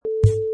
Clips: Snare Bass 1
Rollover or button sound perfect for web pages, flash animation, and other web applications, SAMPLE RATE OPTIMIZED FOR WEBPAGE USE
Product Info: 44k 24bit Stereo
Category: Buttons and Rollovers / Percussion Hits
Try preview above (pink tone added for copyright).
Snare_Bass_1.mp3